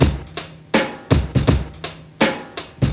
• 82 Bpm 90's Hip-Hop Drum Loop C# Key.wav
Free drum groove - kick tuned to the C# note. Loudest frequency: 1734Hz
82-bpm-90s-hip-hop-drum-loop-c-sharp-key-h7g.wav